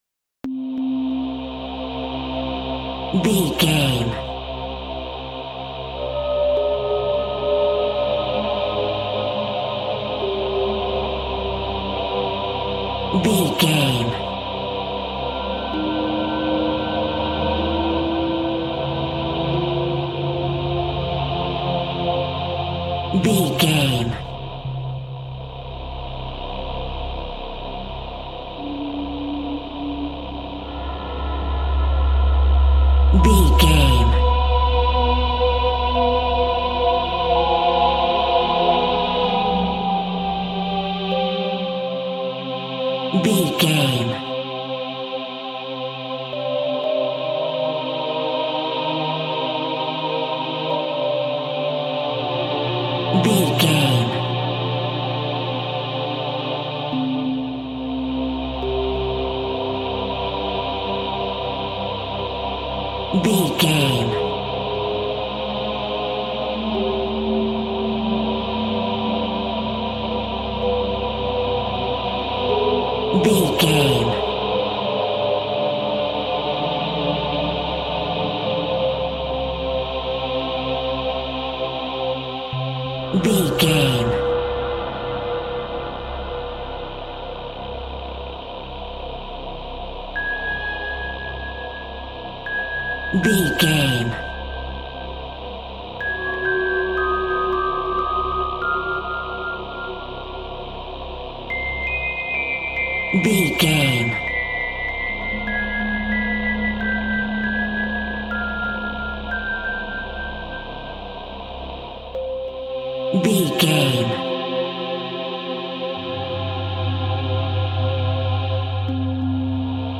Aeolian/Minor
D
scary
ominous
dark
haunting
eerie
piano
percussion
horror music
Horror Pads
Horror Synths